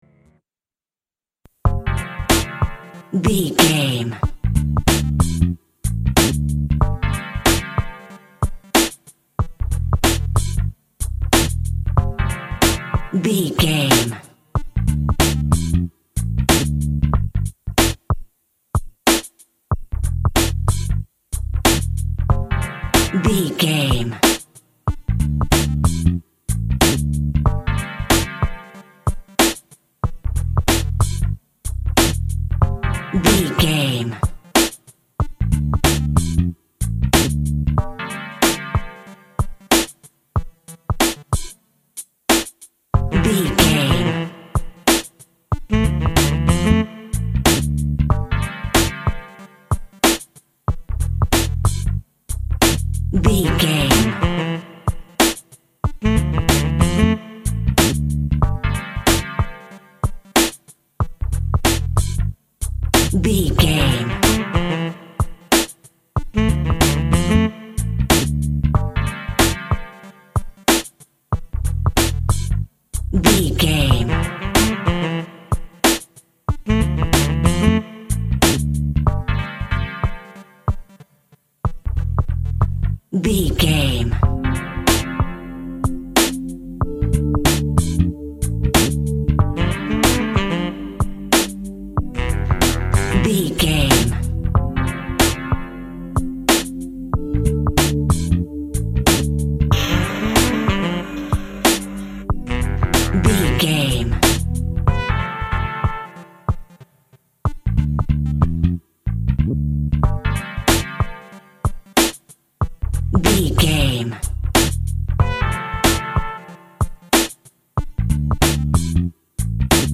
Aeolian/Minor
synth lead
synth bass
hip hop synths
electronics